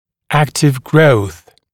[‘æktɪv grəuθ][‘эктив гроус]активный рост